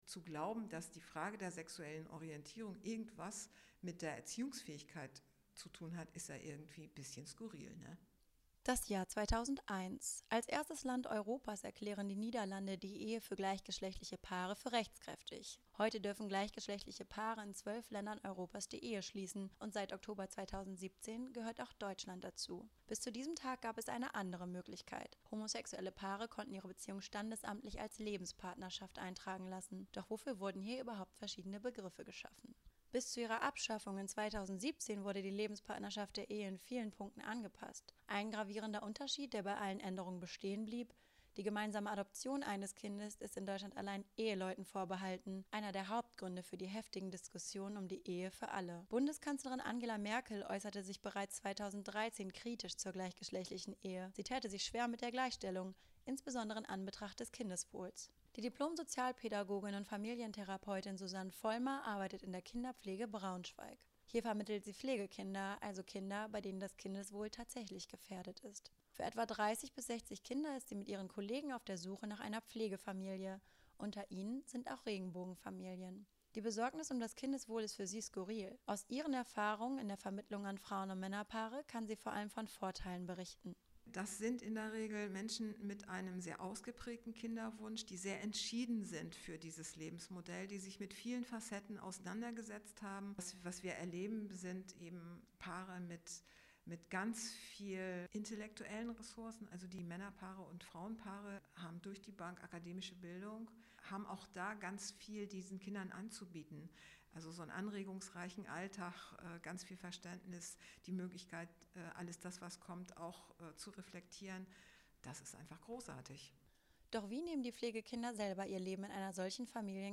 Werden Kinder gleichgeschlechtlicher Paare diskriminiert? Campus38 spricht mit einer Sozialpädagogin, einer Regenbogenfamilie und einem katholischen Priester über ihre Erfahrungen.